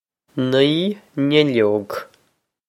Pronunciation for how to say
nee nill-ohg
This is an approximate phonetic pronunciation of the phrase.